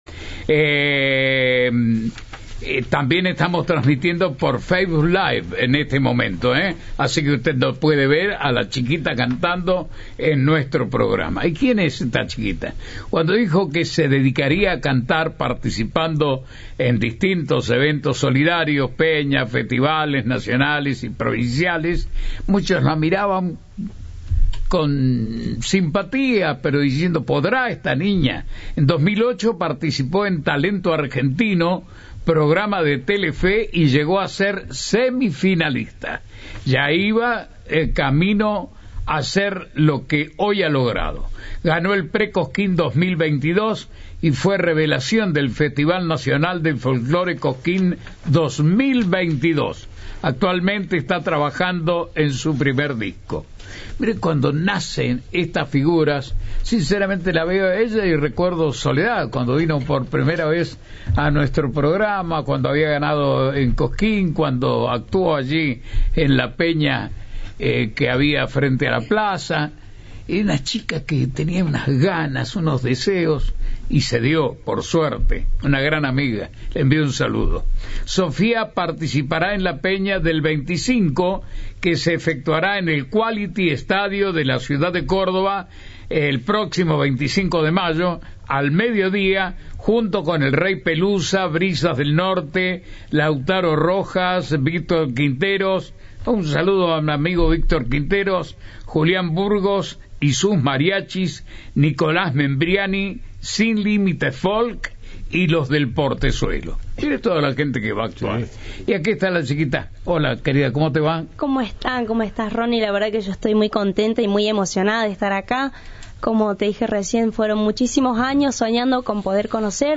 Antes, cantó en vivo para Cadena 3.